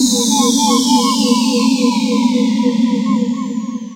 Eerie_Ambi_Fx.wav